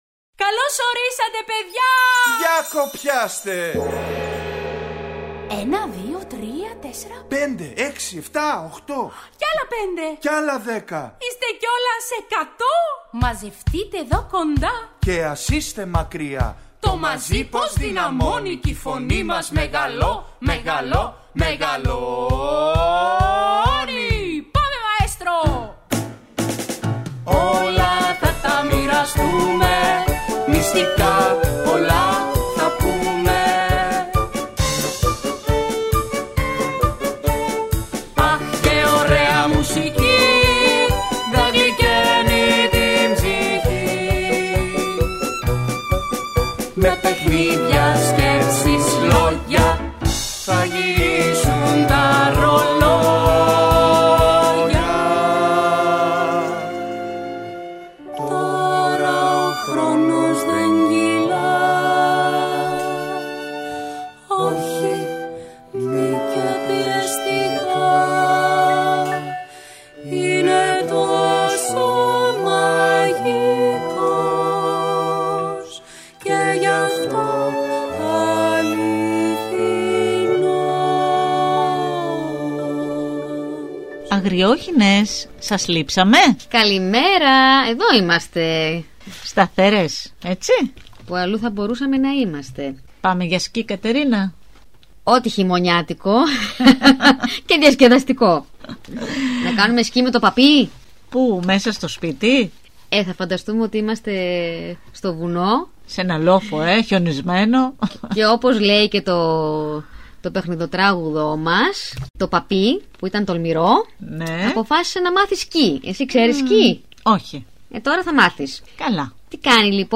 Ακούστε στην παιδική εκπομπή ‘’Οι Αγριόχηνες’’ το παραμύθι «Το σπουργιτάκι» του Μαξίμ Γκόργκι.